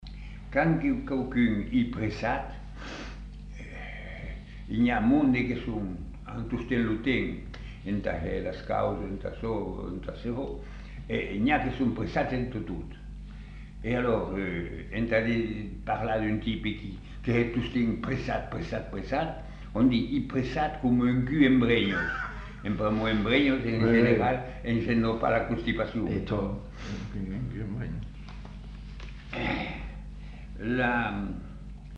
Aire culturelle : Savès
Genre : forme brève
Effectif : 1
Type de voix : voix d'homme
Production du son : récité
Classification : locution populaire